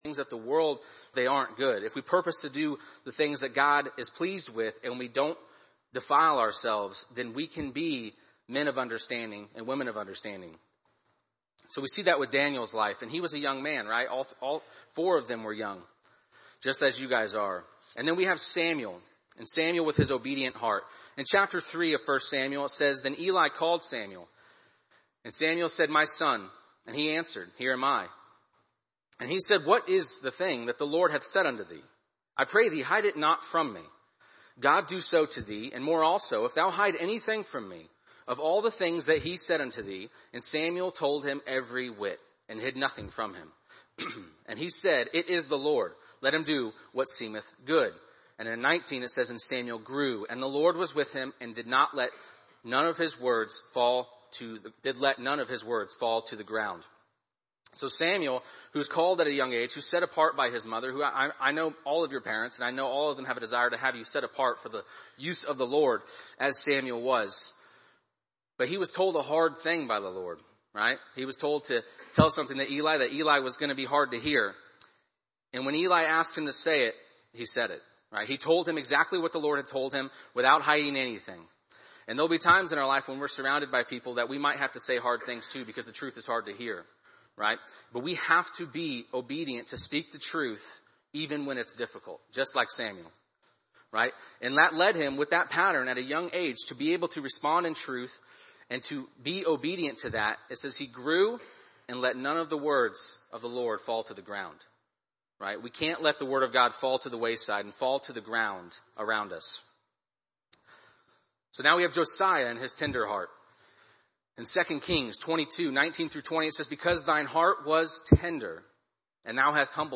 Sermons | Heartland Baptist Fellowship
Thou Shalt Not Kill Kingdom Seeker Shield Ceremony at the beginning Getting Out of Egypt...